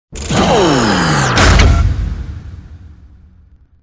bladesoff.wav